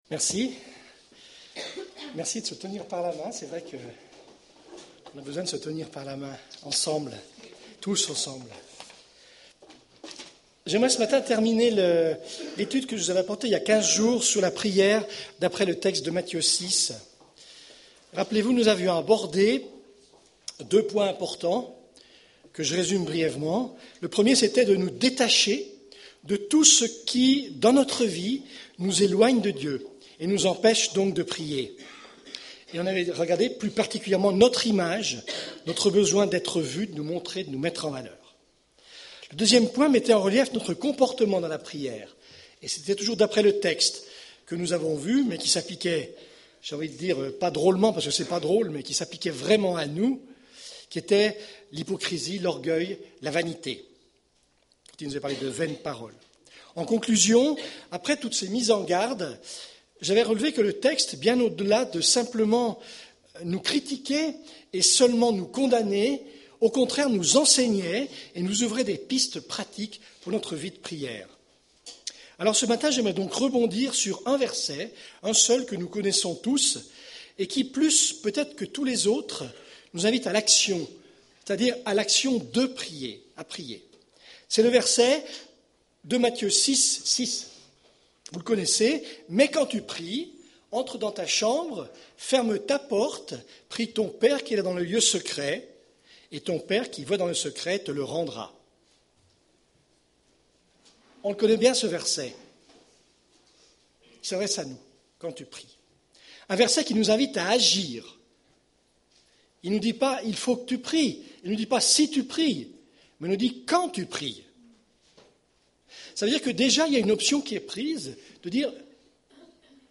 Preacher
Culte du 10 février